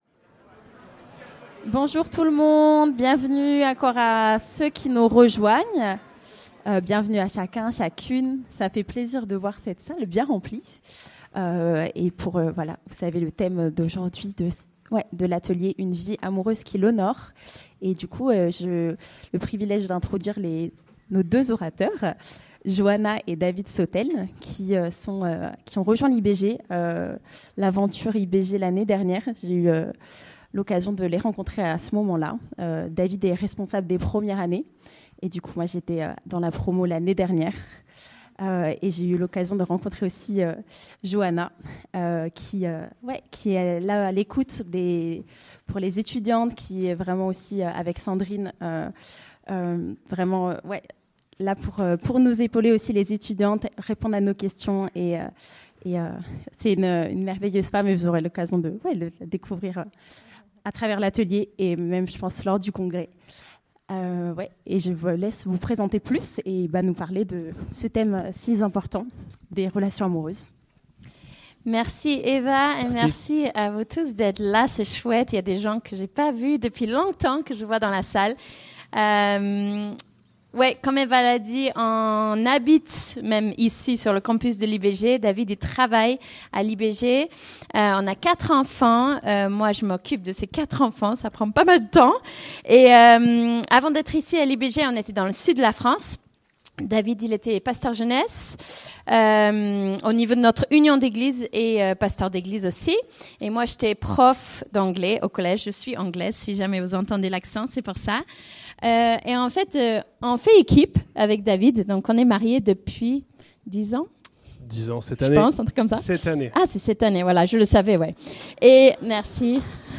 Ateliers Pâques 2025, Vivant